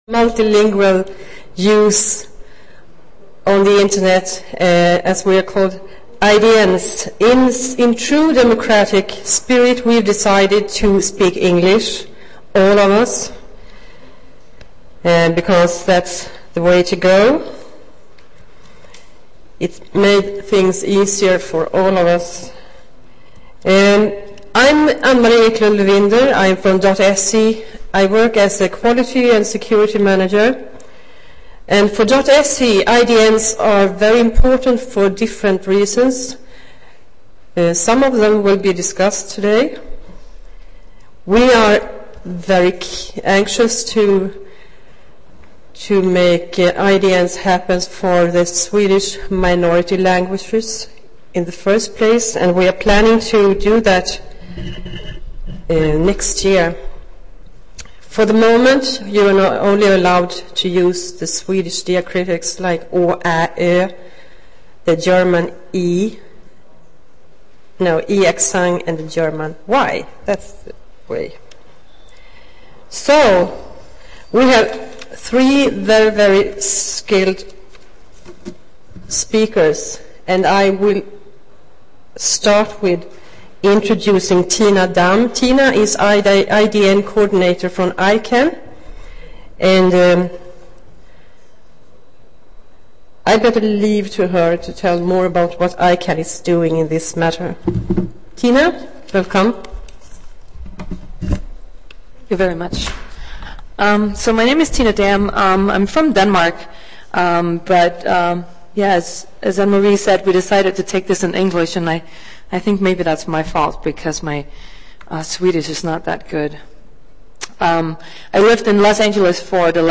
Seminariet belyser status f�r IDN ur n�gra olika perspektiv.